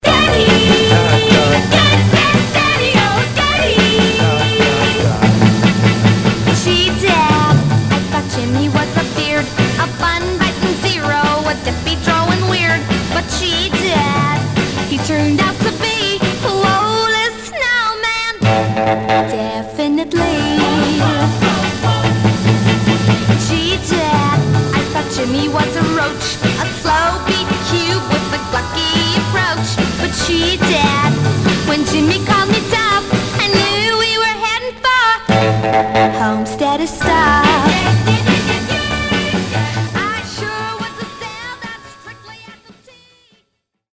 Some surface noise/wear Stereo/mono Mono
White Teen Girl Groups